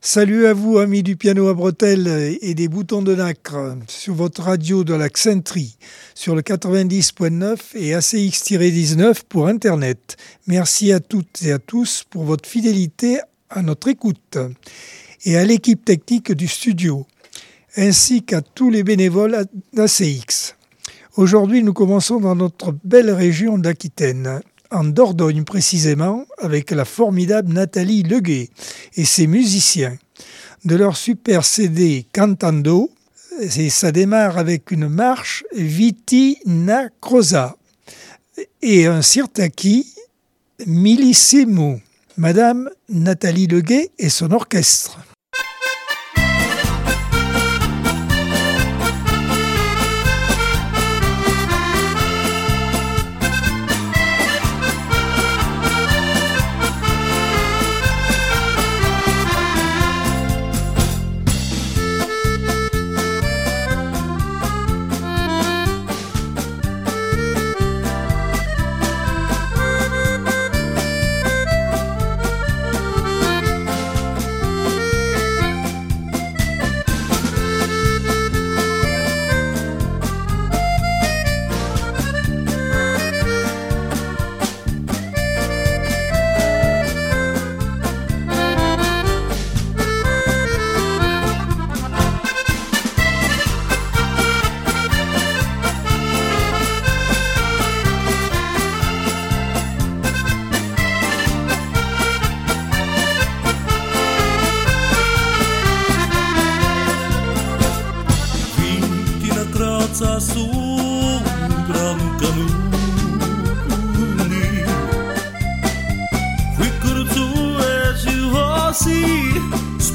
Accordeon 2026 sem 03 bloc 1 - Radio ACX